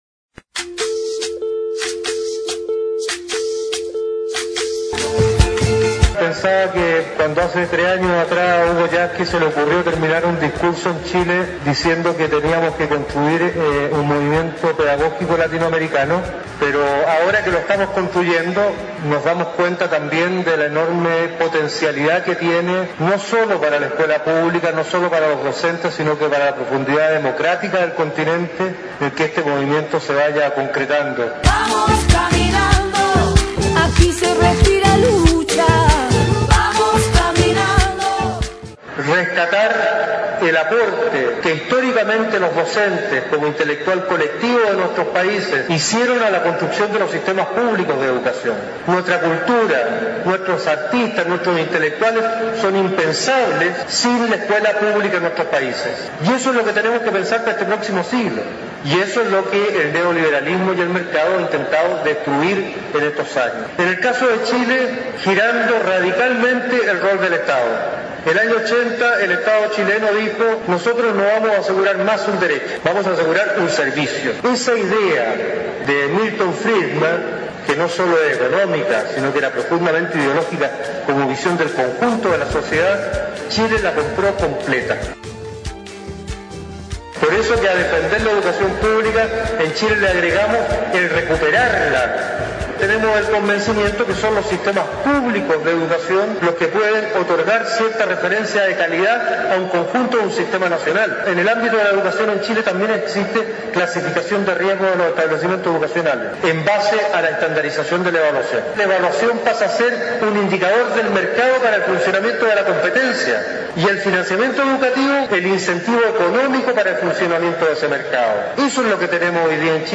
Ponencias
Congreso Educativo